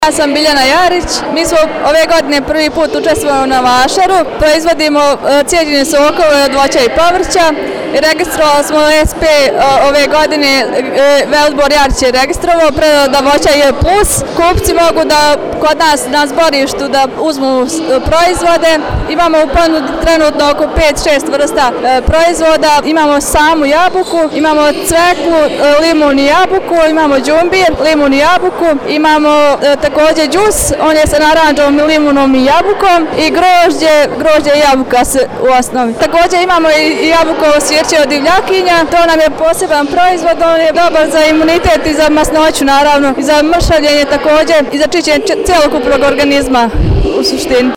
izjavu